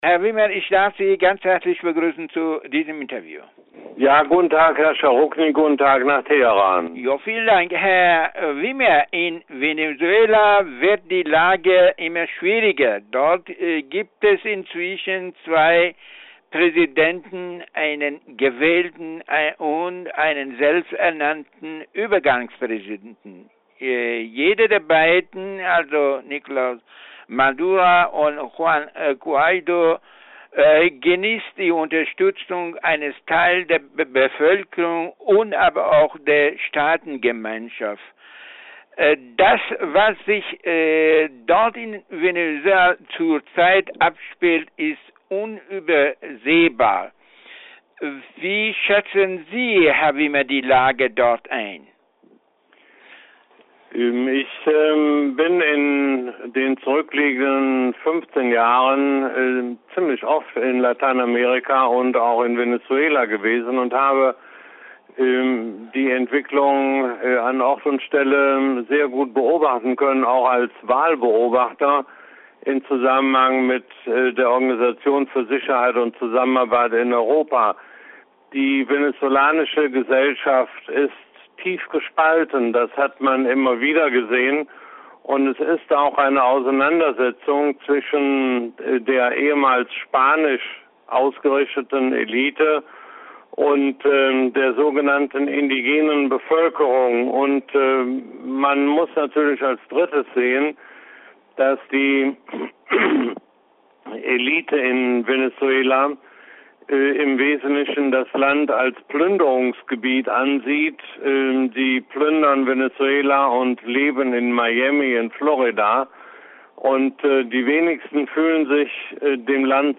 Interview mit Willy Wimmer